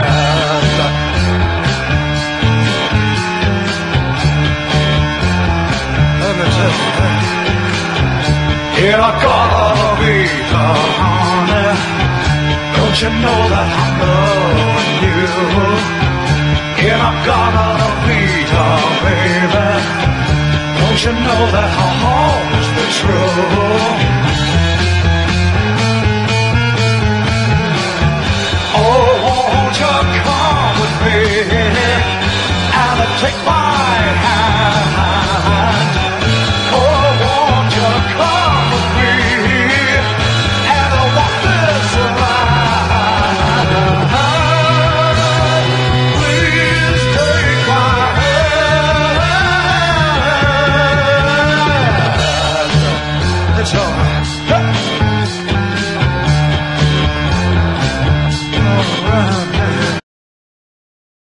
なベース・ラインとパーカッシヴなサウンドでGARAGEファンにも人気の名曲